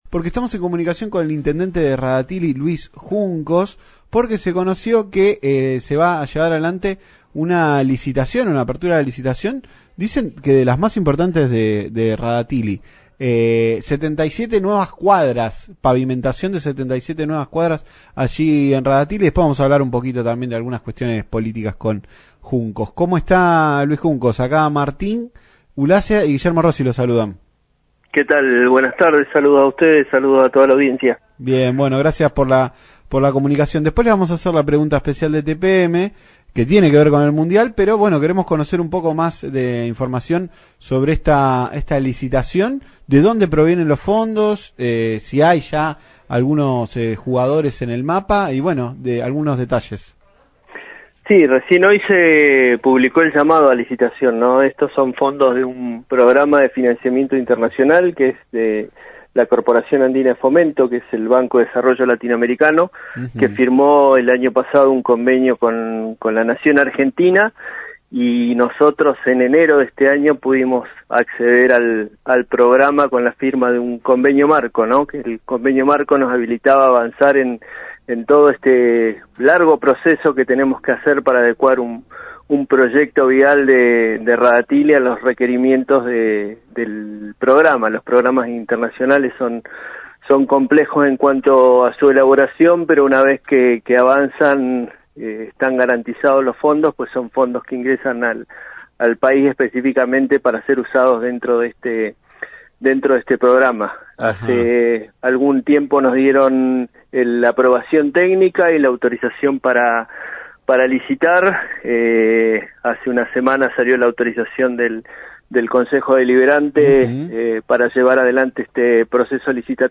Luis Juncos, intendente de Rada Tilly, habló en Tarde Para Miles por LaCienPuntoUno sobre la obra para avanzar en la pavimentación de 77 cuadras en ocho sectores de la ciudad balnearia.